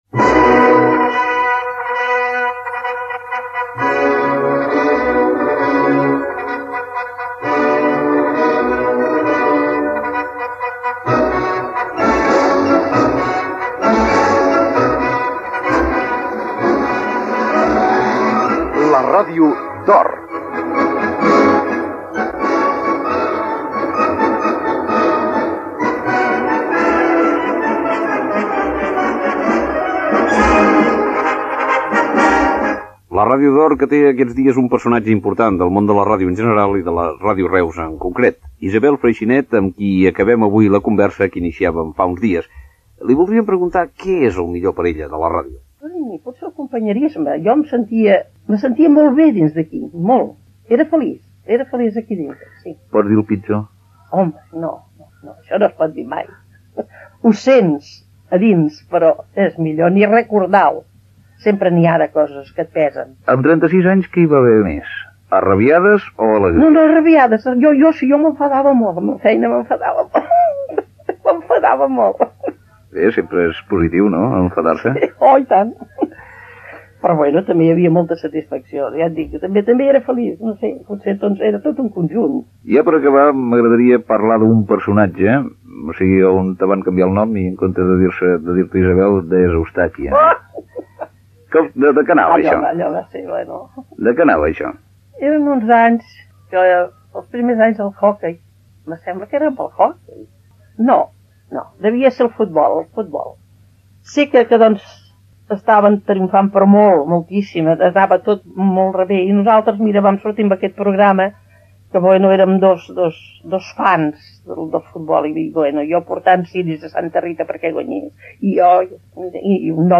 La ràdio d'or: entrevistada - Ràdio Reus, 1985
Àudio: arxiu sonor de Ràdio Reus